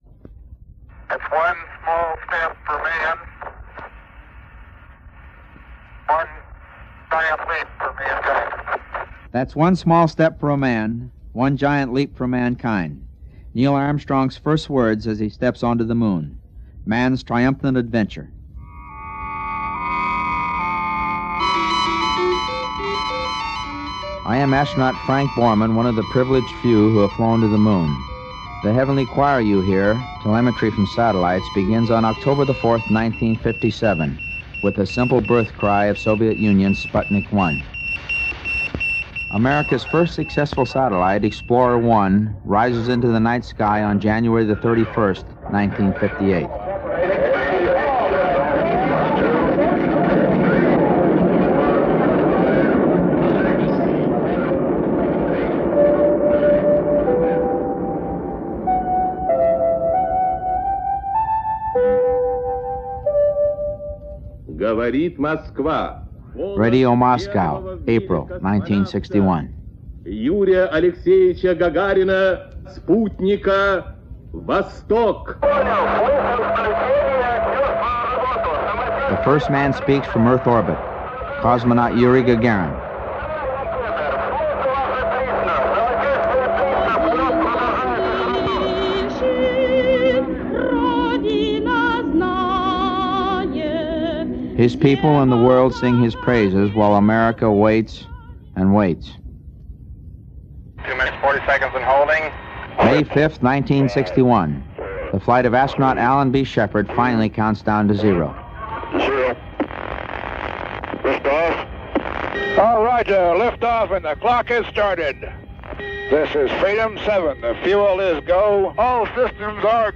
E ripulito in mp3 (circa 4 mega per lato) ho convertito pure Sounds of the Space Age, un vinile flessibile allegato, anzi, da strappare dall’interno del numero di dicembre '69 del National Geographic. Un riassunto dallo Sputnik all’Apollo 11 narrato da Frank Borman.